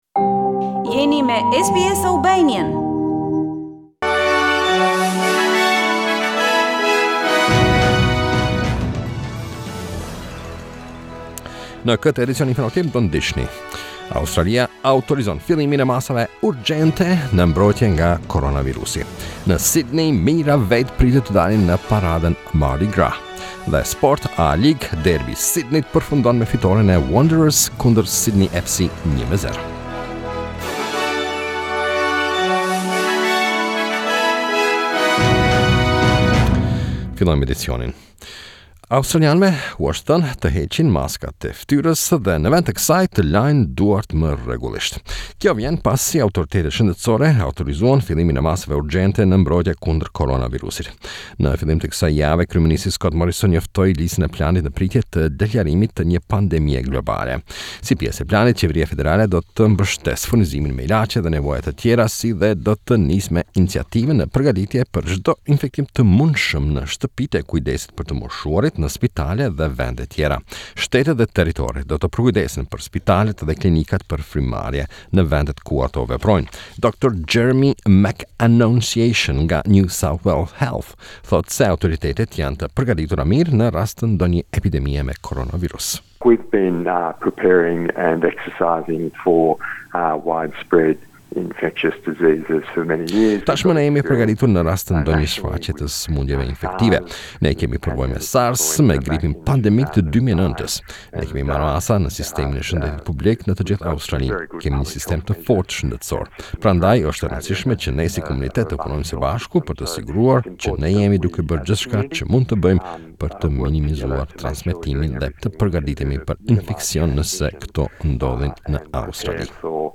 SBS News Bulletin 29 February 2020